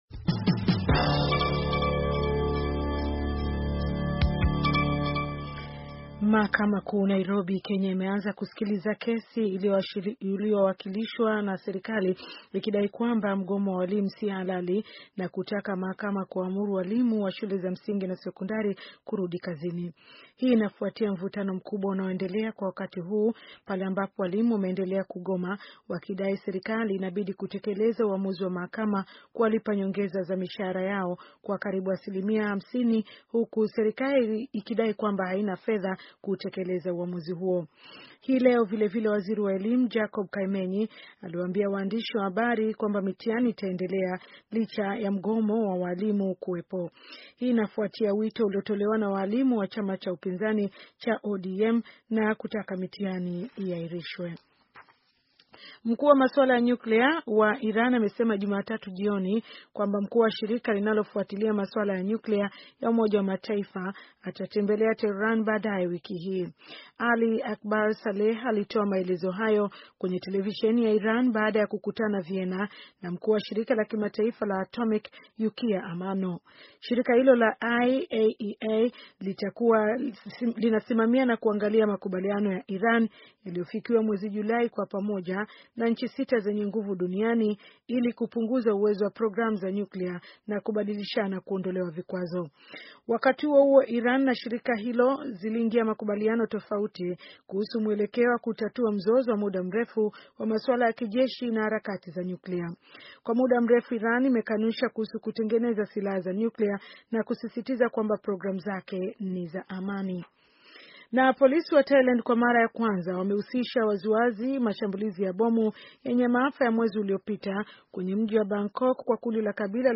Taarifa ya habari - 5:05